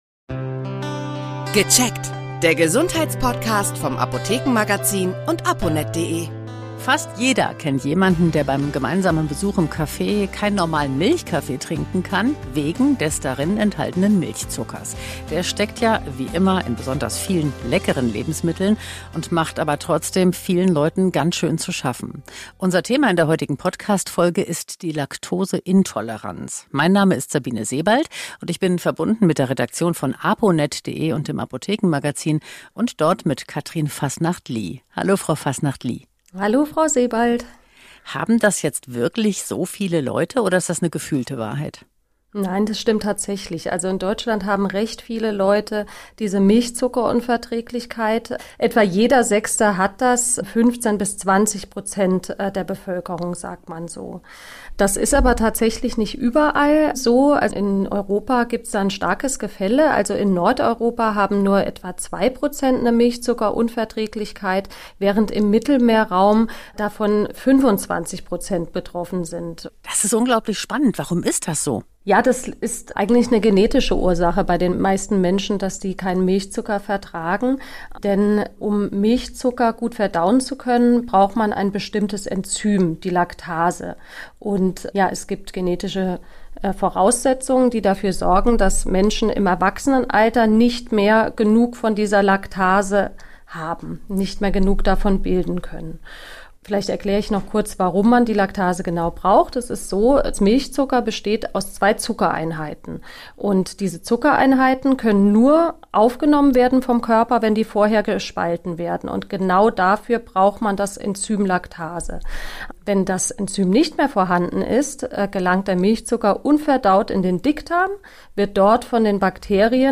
Ernährungswissenschaftlerin erklärt Ursachen, Tests und Alltagshilfen.